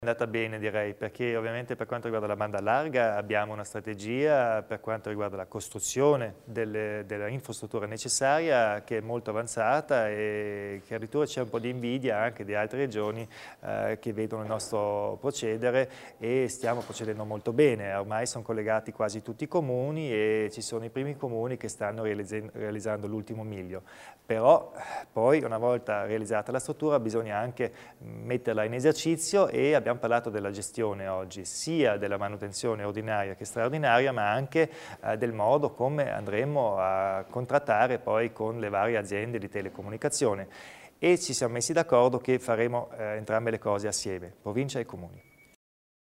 Il Presidente Kompatscher elenca i prossimi passi nella gestione della rete a fibra ottica